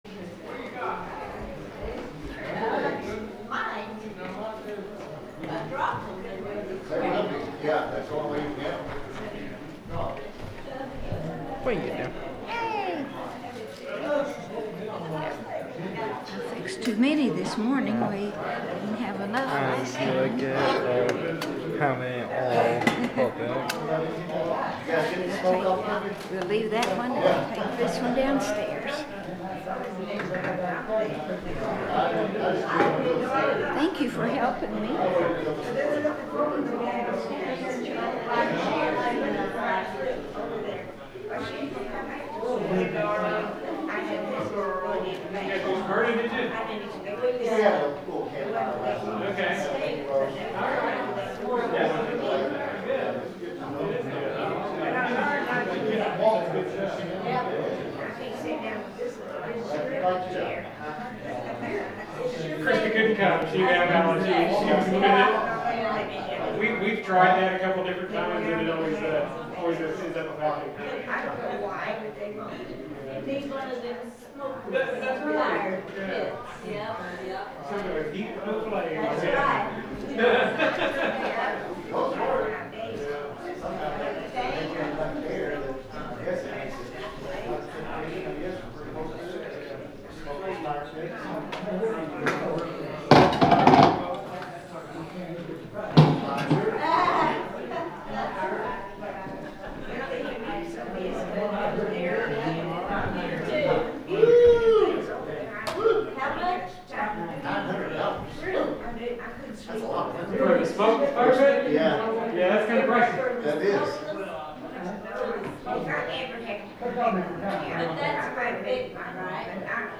The sermon is from our live stream on 10/26/2025